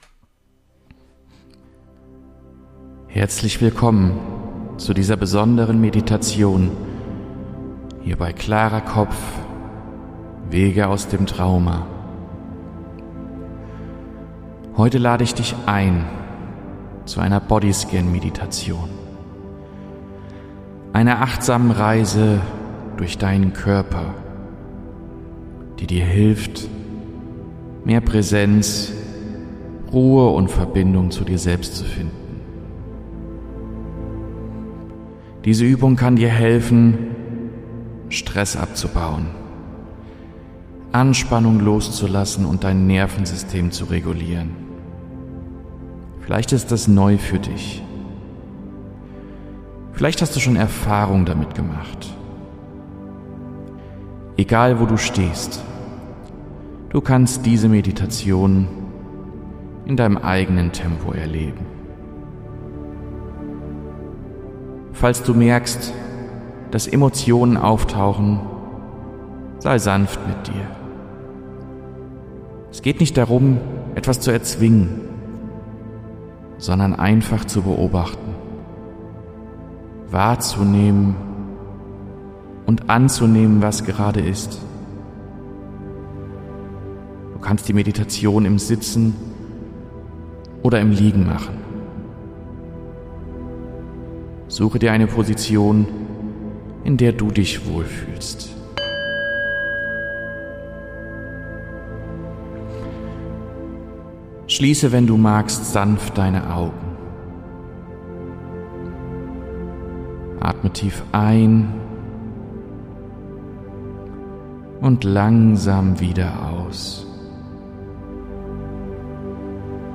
Bonus: Body-Scan-Meditation